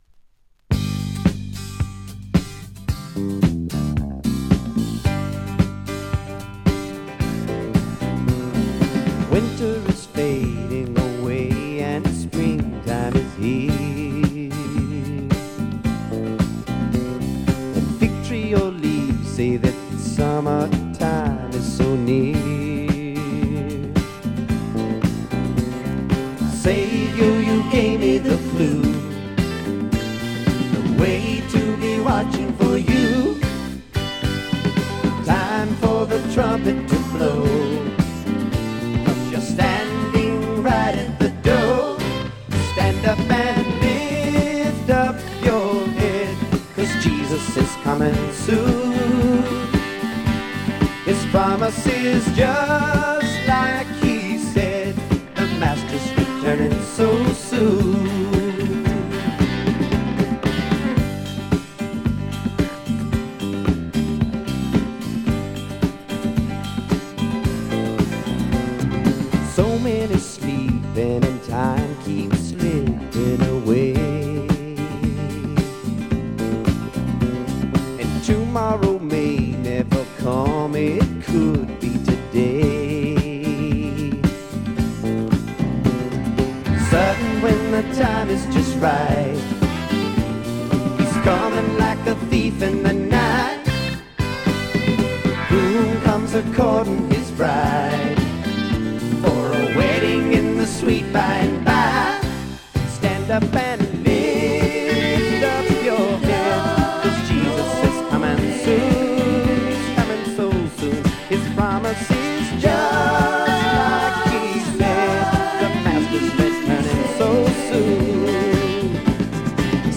アコースティックな作品もあってとても良いアルバムと思います。